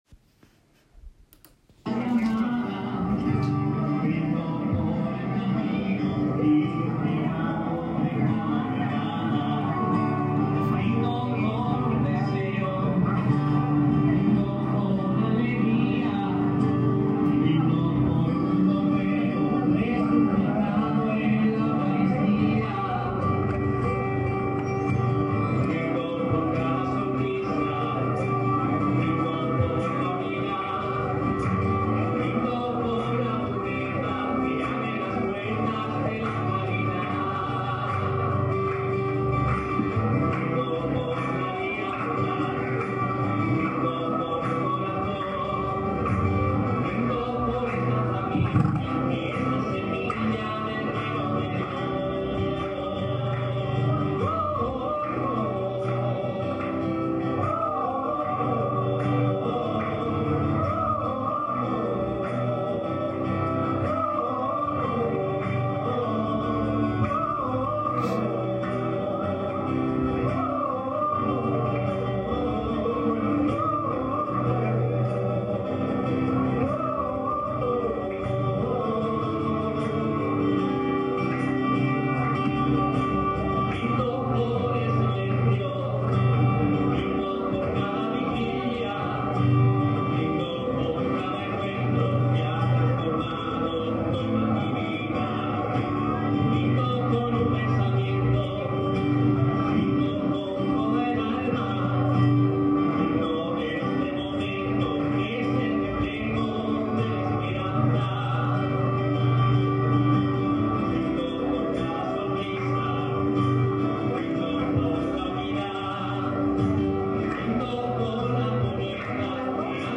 Original tomado en directo.